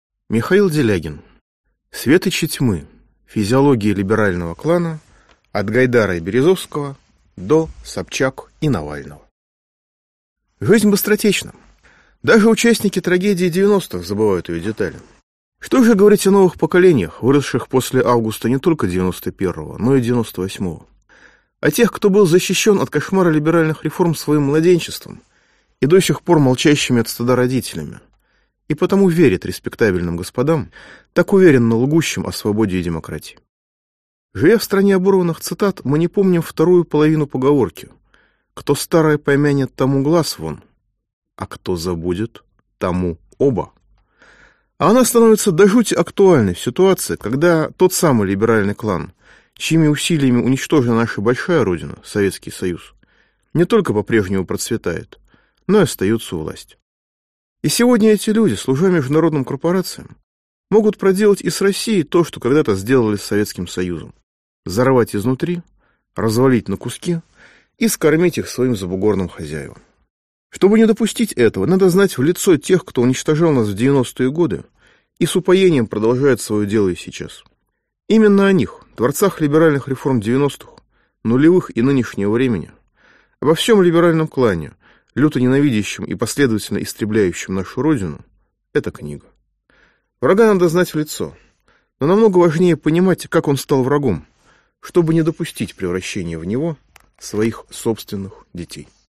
Физиология либерального клана: от Гайдара и Березовского до Собчак и Навального Автор Михаил Делягин Читает аудиокнигу Михаил Делягин. Прослушать и бесплатно скачать фрагмент аудиокниги